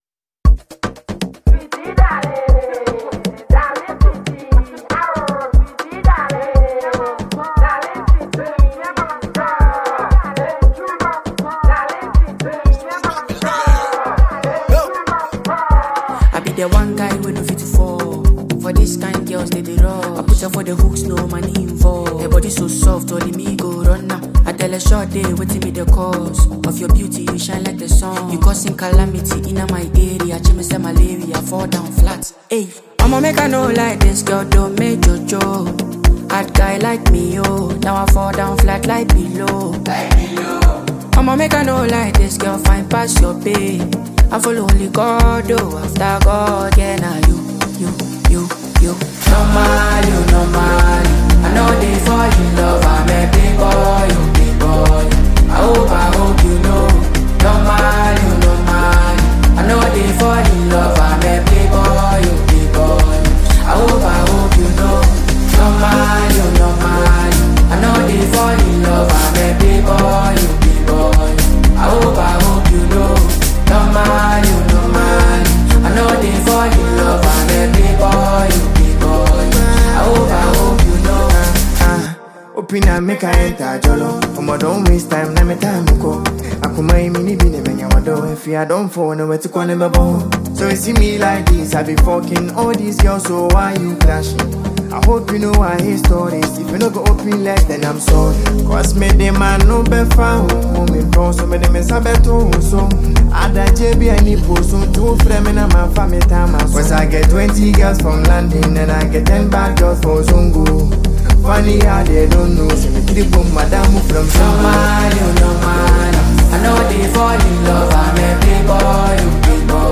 Genre: Afrobeat